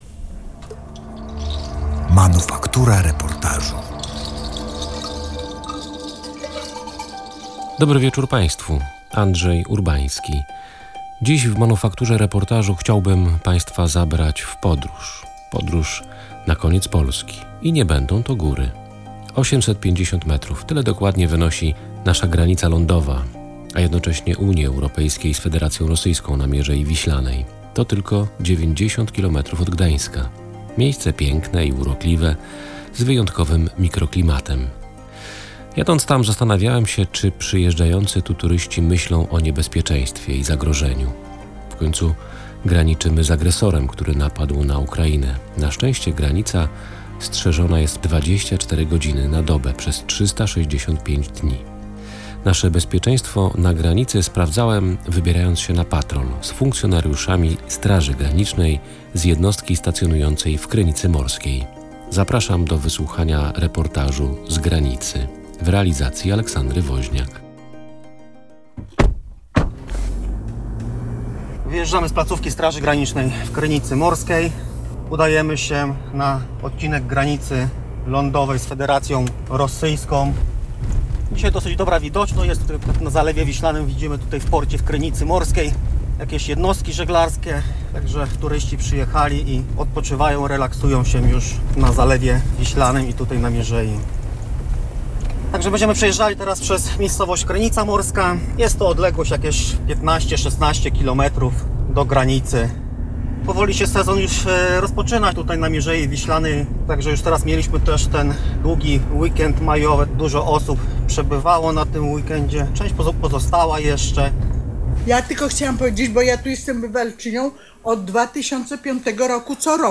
Pilnują polskiej granicy z Federacją Rosyjską. Reportaż „Z granicy”
Posłuchaj reportażu „Z granicy”: